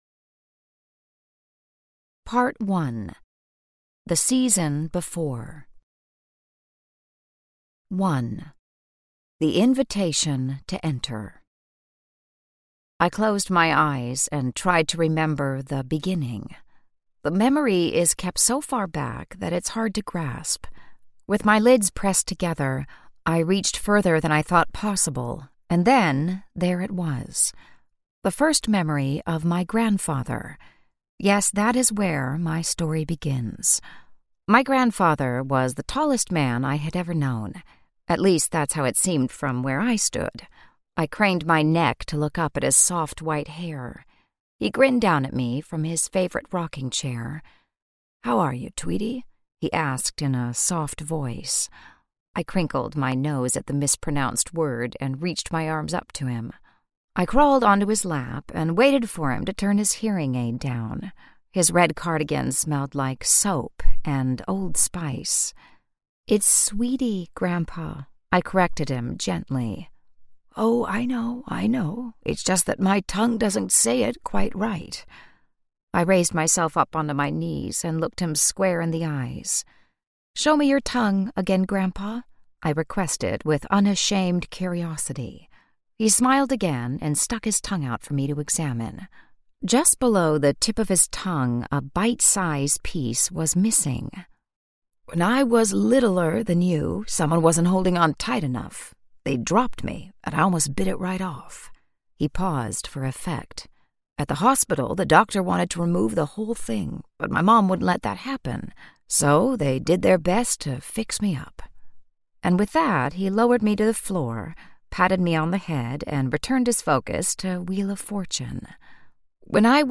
Keep the Doors Open Audiobook
Narrator
6.0 Hrs. – Unabridged